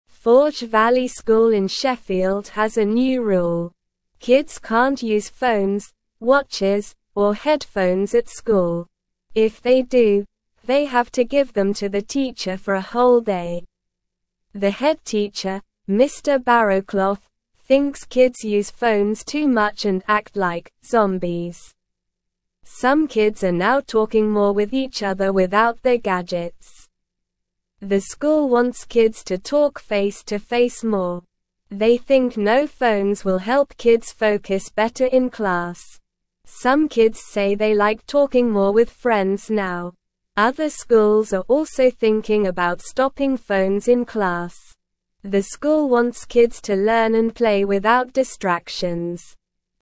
Slow
English-Newsroom-Beginner-SLOW-Reading-No-Phones-Allowed-at-Forge-Valley-School-in-Sheffield.mp3